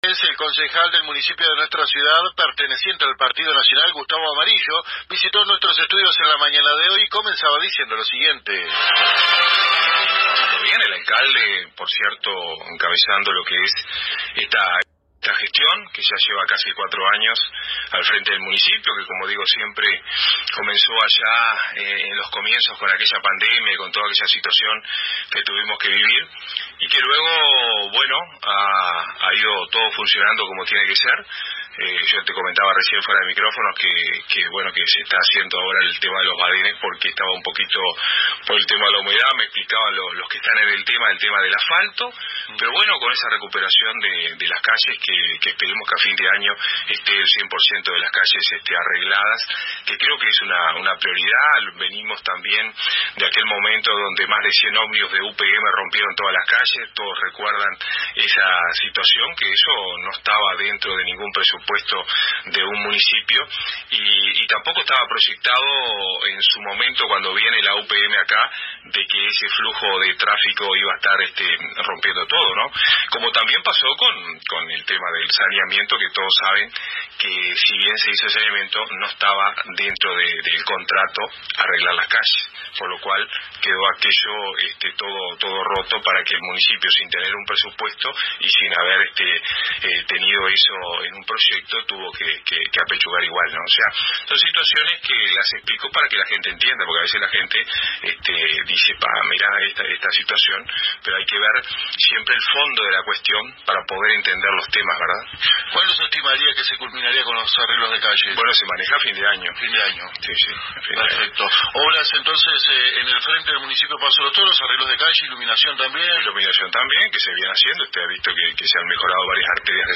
El Concejal isabelino por el Partido Nacional, Gustavo Amarillo, de visita en los estudios de la AM 1110 local reiteró que los trabajos que viene adelantando el Municipio Paso de los Toros para el mejoramiento de la red vial de la ciudad estarán prontos para finales de año, a saber construcción de badenes y cordón cunetas, asfaltado, saneamiento y luminarias.